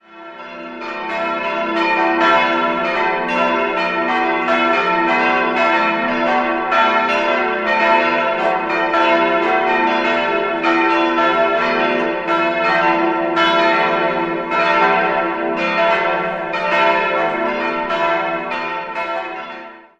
Dieses musste nach einem Brand im Jahr 1950 schon wieder erneuert werden. 1983-1985 kam es abermals zu einem Kirchenneubau. 4-stimmiges Geläut: e'-fis'-a'-h' Die drei kleineren Glocken wurden 1950 von Czudnochowsky und die große Glocke 1984 von Perner gegossen.